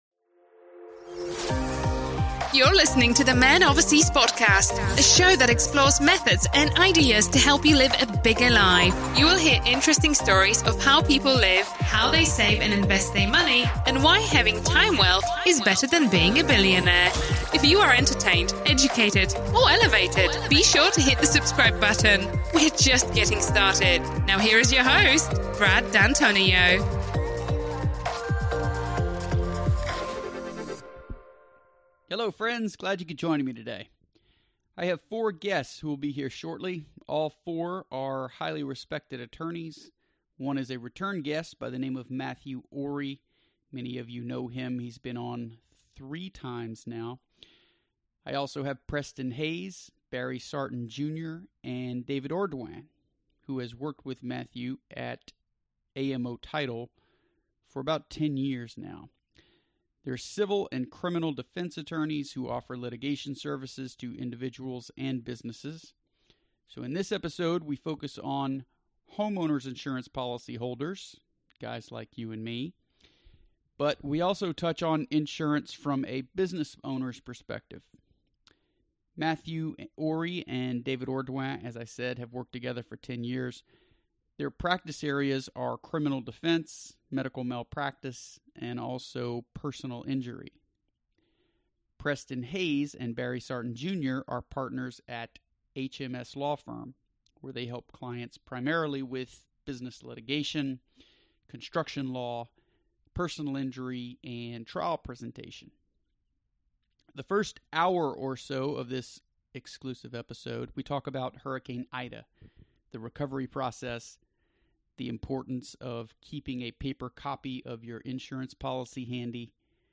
All are highly-respected civil & criminal attorneys who offer litigation services to individuals and businesses.